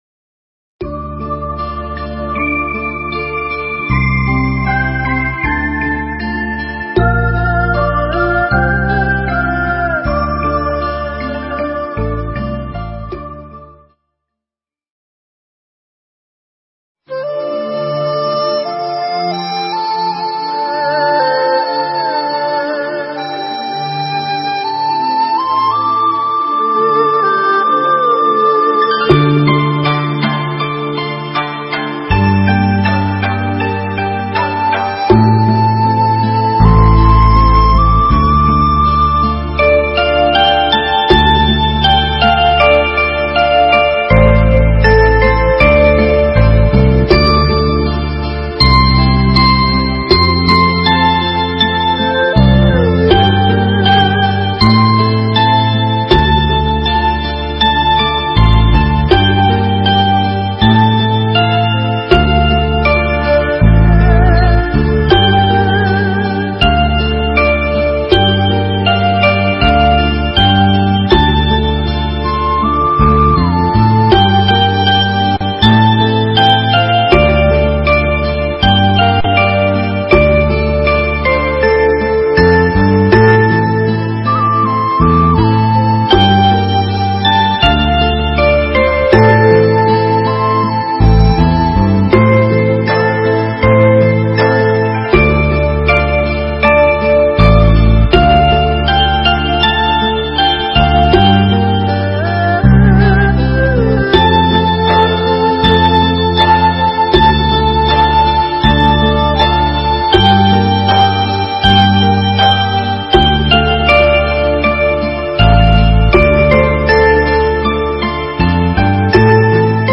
Mp3 thuyết pháp Hướng Đến Đời Sống Tâm Linh